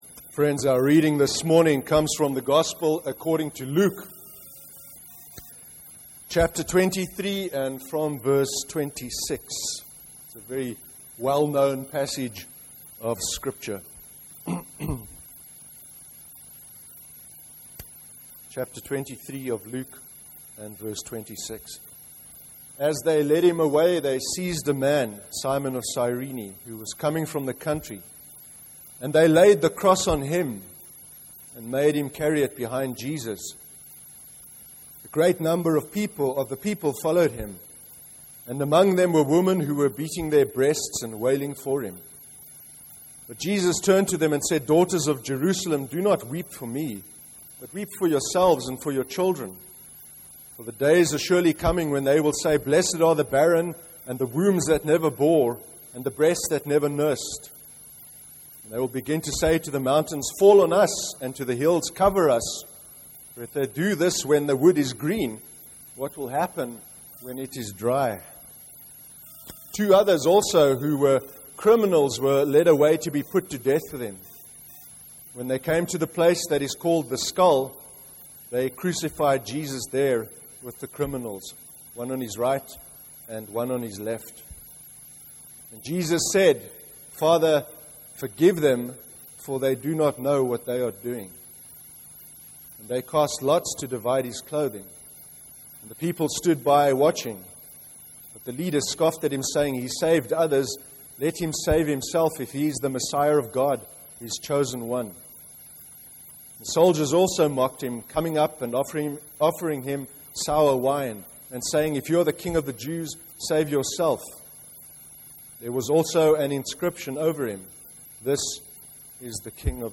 Click on the player below to hear the service, or right click on this link to download the sermon to your computer: